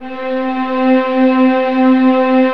VIOLINS DN-L.wav